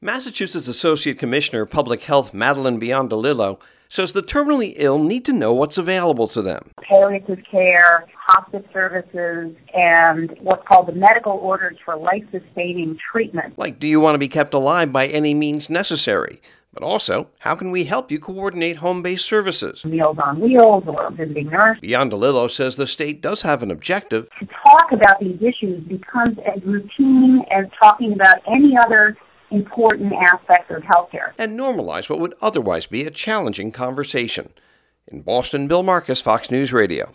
MASSACHUSETTS ASSOCIATE COMMISSIONER OF PUBLIC HEALTH DOCTOR MADELINE BIONDOLILLO SAYS IT IS IMPORTANT FOR PATIENTS TO BE INFORMED OF WHAT THEIR END OF LIFE CHOICES ARE.